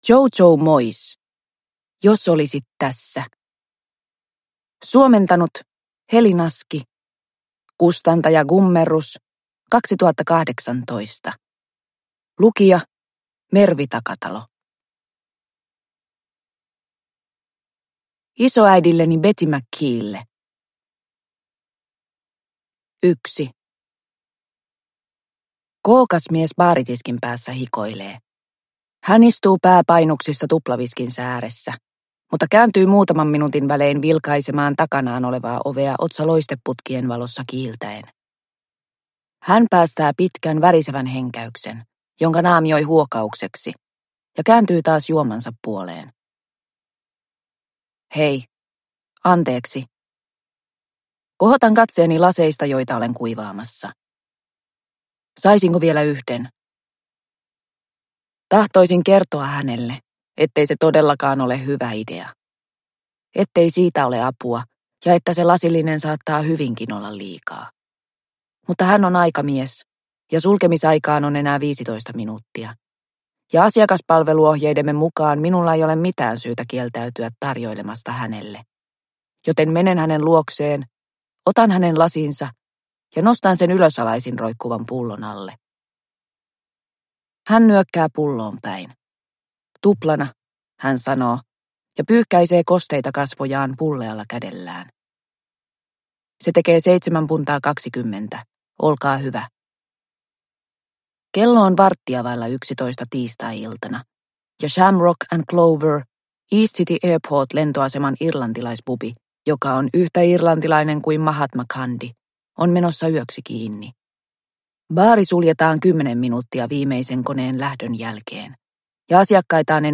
Jos olisit tässä – Ljudbok – Laddas ner